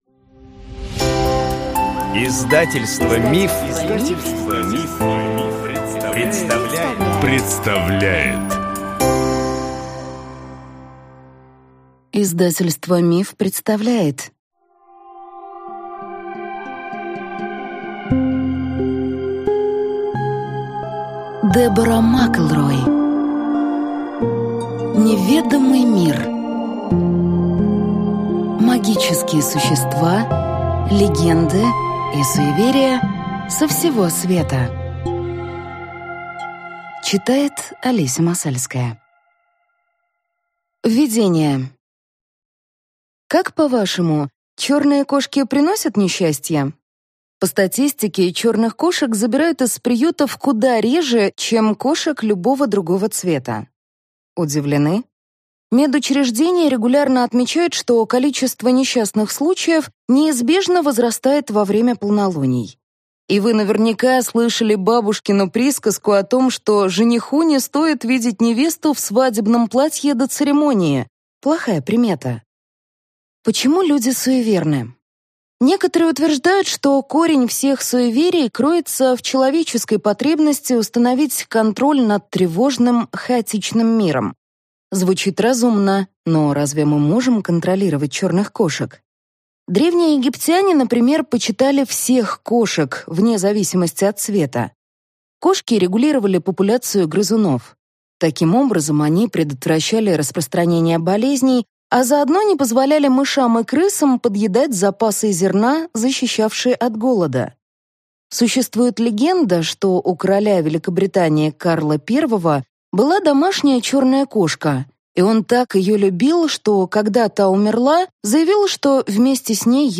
Аудиокнига Неведомый мир. Магические существа, легенды и суеверия со всего света | Библиотека аудиокниг